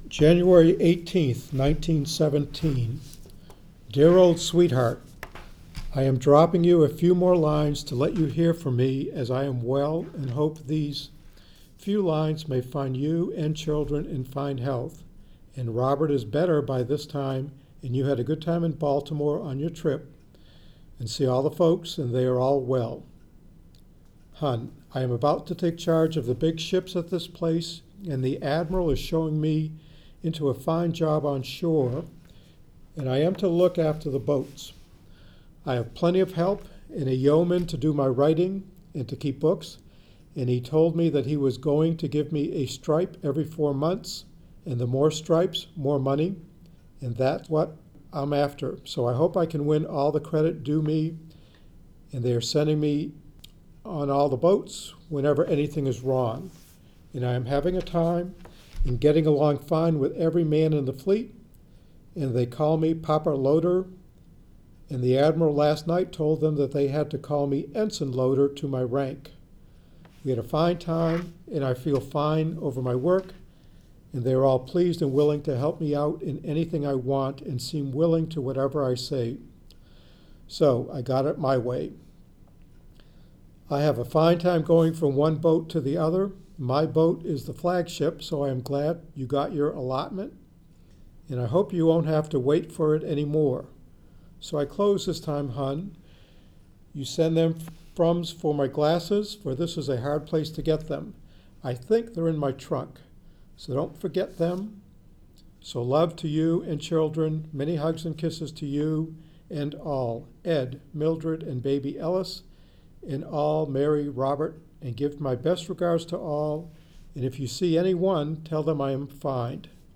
We are truly honored to have local U.S. military veterans from different backgrounds read portions of the Letters Home Collection from The Mariners’ Museum Library.